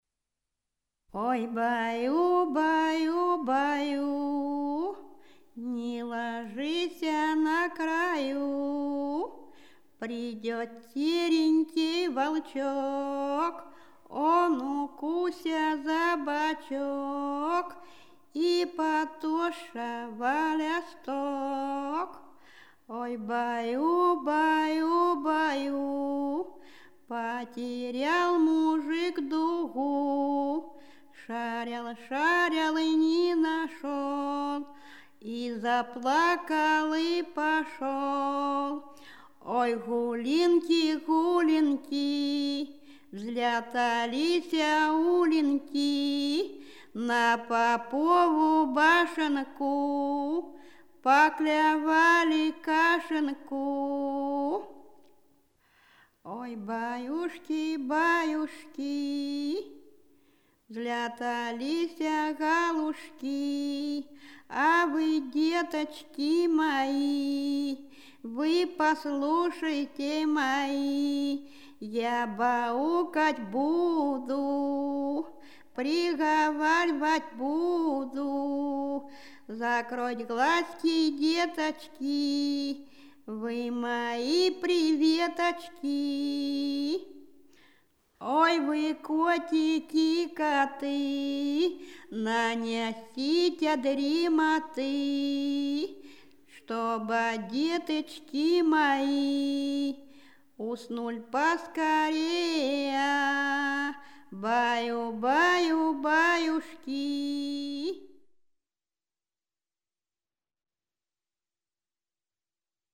Рязань Секирино «Ой, баю, баю, баю», байканье.